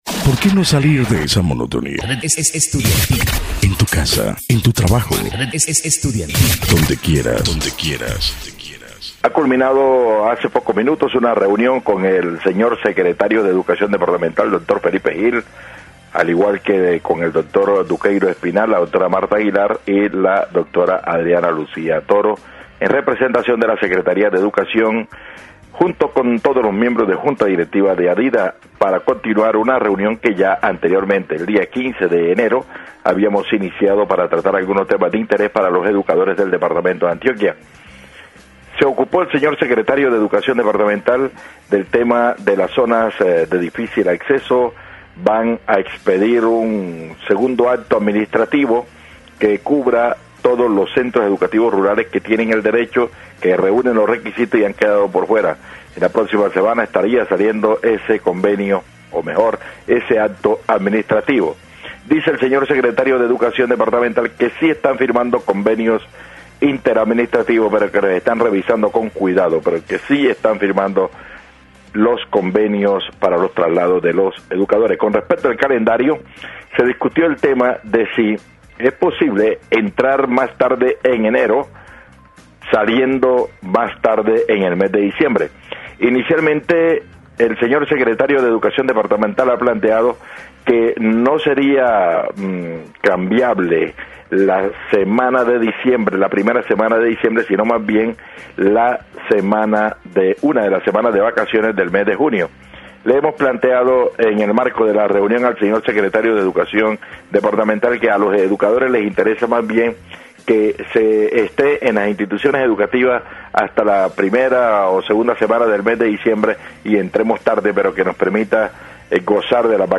A continuación les dejamos el audio donde se tocan los temas, es tomado del programa educación al día